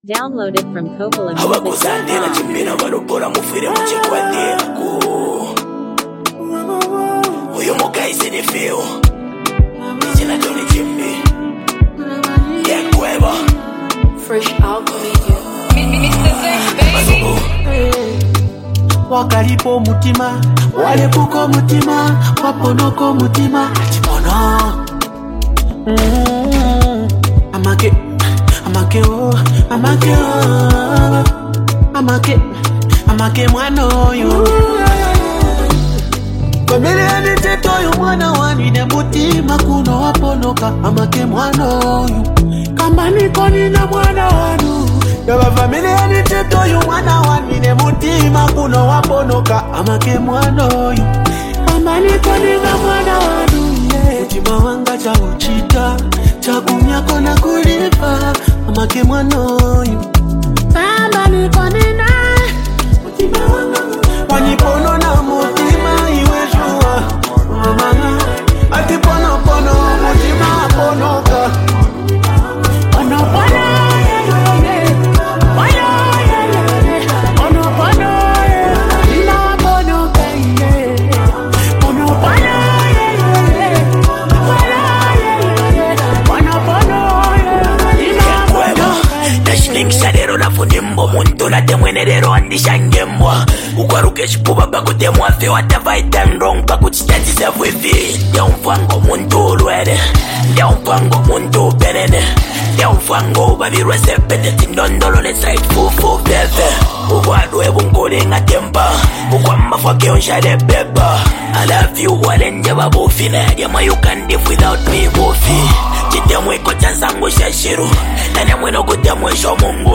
smooth and heartfelt song
soulful vocals
With its calm melody and relatable storytelling